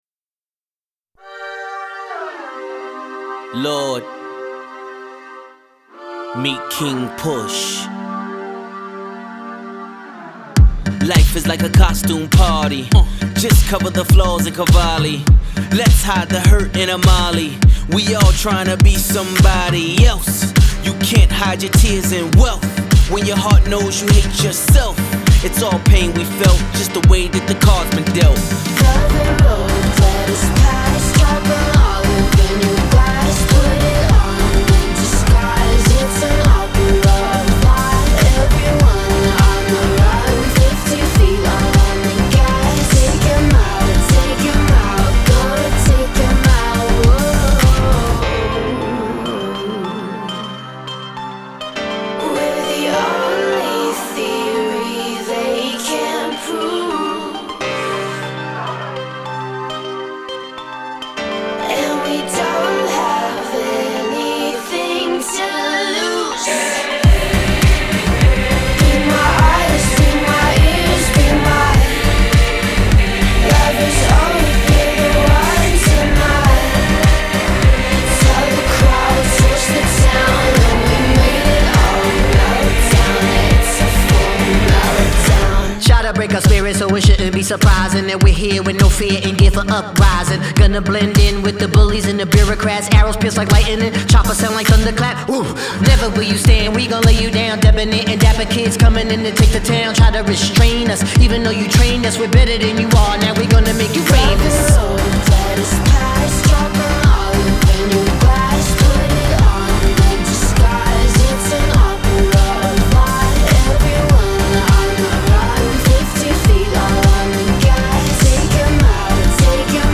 an ensemble for the ages.